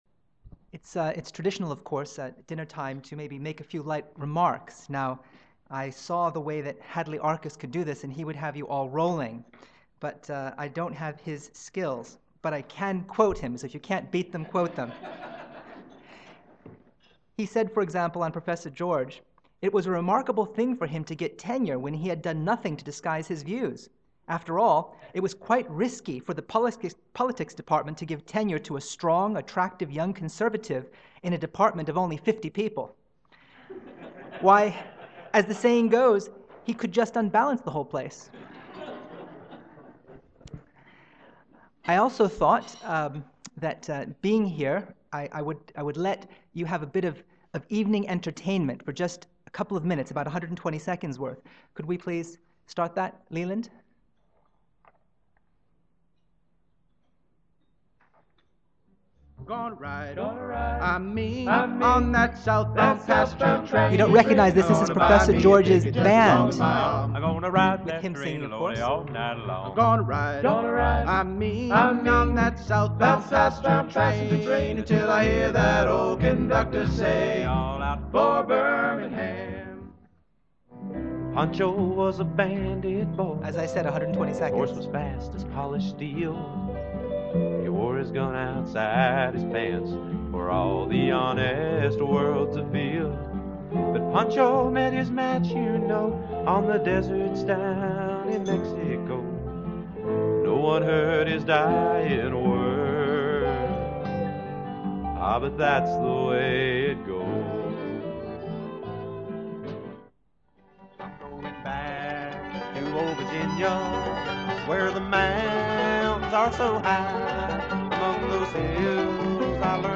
Wed. Evening Dinner Address